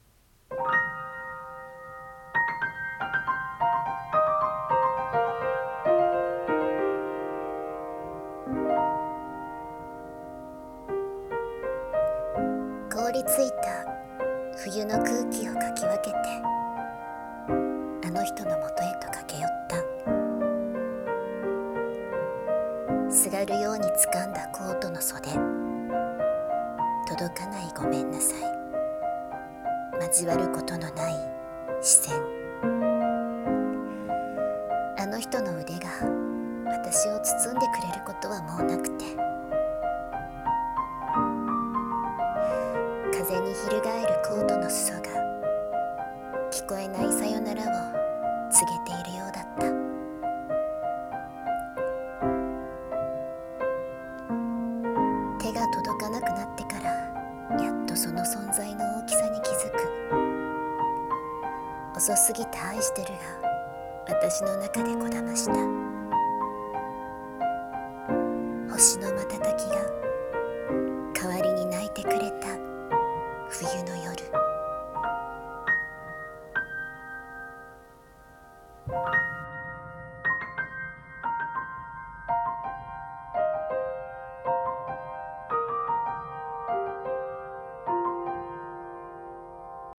【声劇1人用】